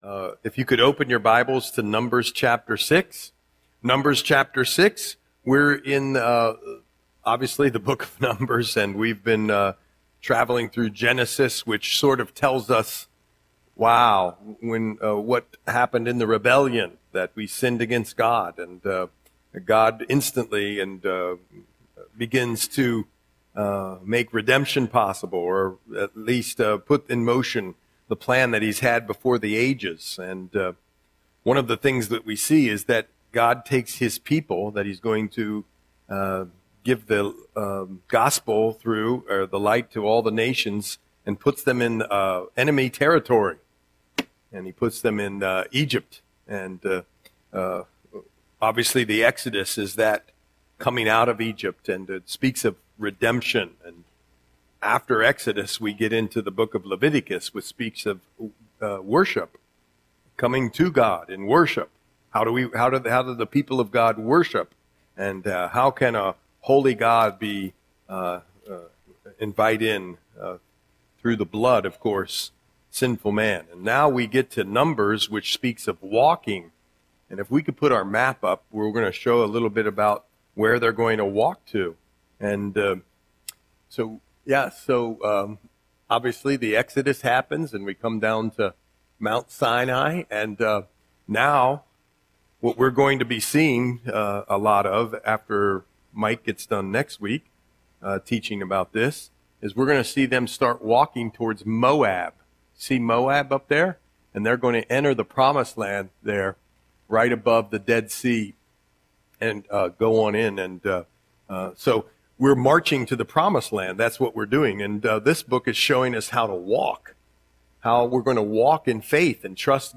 Audio Sermon - February 25, 2026